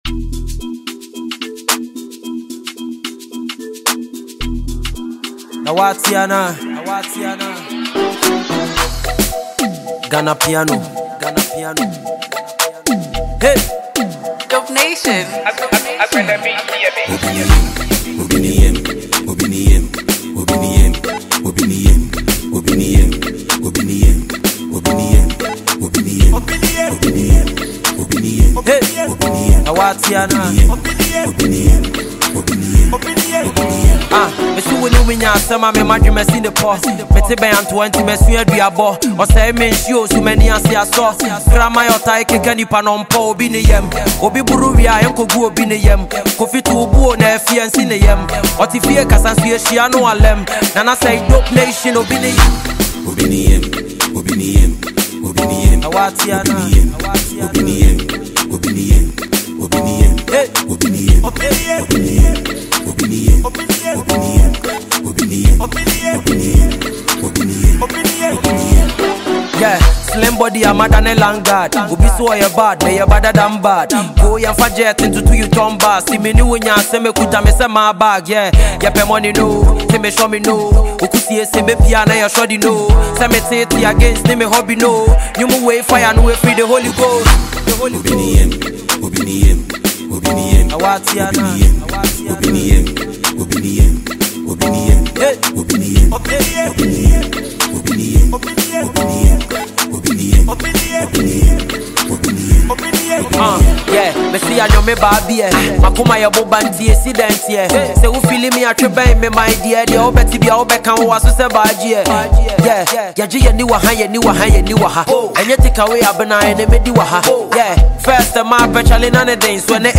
Multiple award-winning Ghanaian rapper